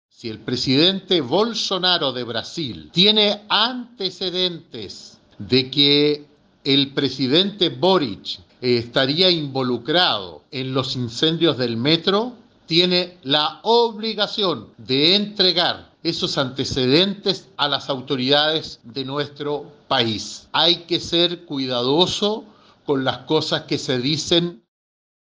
En tanto, el senador UDI Iván Moreira, quien pertenece a la Comisión de Relaciones Internacionales de la Cámara Alta, sostuvo que no corresponde que ningún presidente extranjero intervenga en asuntos de nuestro país, e invitó a Bolsonaro a presentar antecedentes de su acusación.